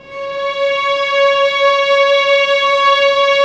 Index of /90_sSampleCDs/Roland LCDP13 String Sections/STR_Violins II/STR_Vls6 p%mf St